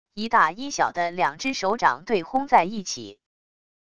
一大一小的两只手掌对轰在一起wav音频